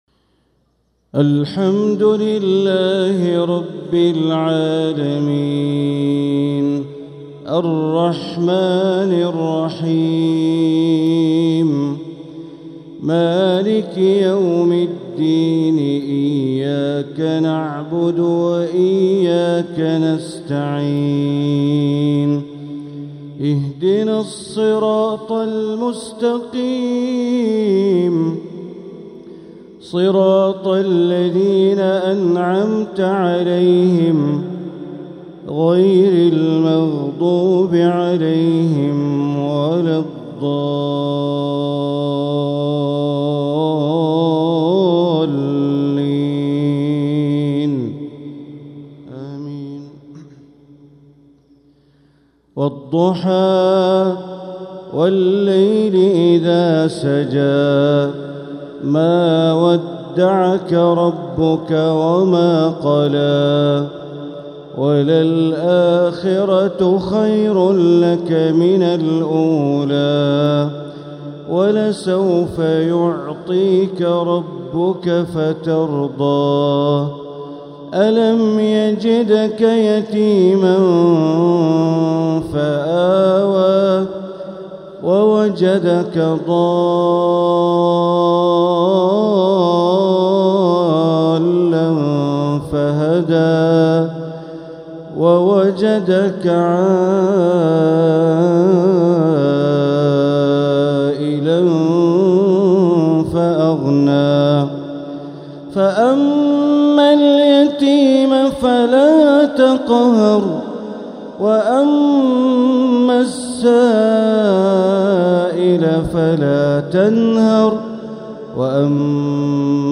تلاوة لسورتي الضحى و الشرح | مغرب الجمعة 20 شوال 1446هـ > 1446هـ > الفروض - تلاوات بندر بليلة